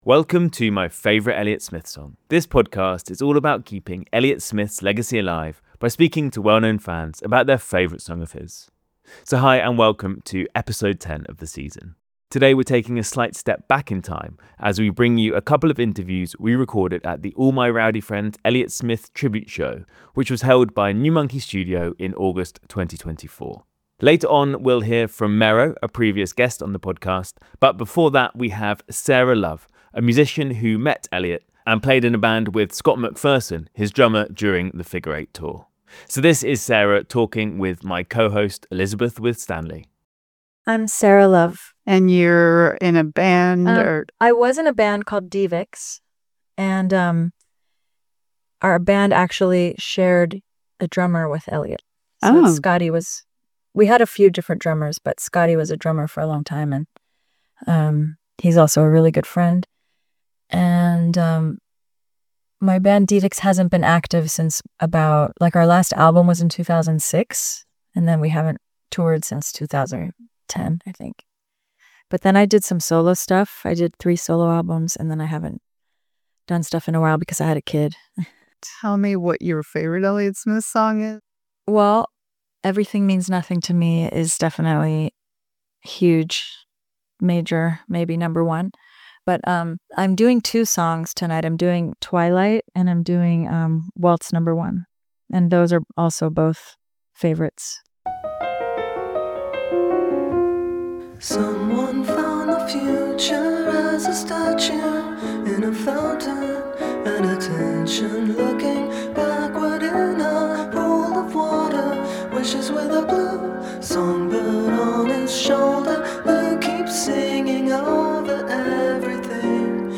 We're bringing you two interviews recorded at New Monkey Studio's All My Rowdy Friends tribute show, which was held in Los Angeles in 2024.
Both interviews were recorded before our guests took to the stage at the fantastic event.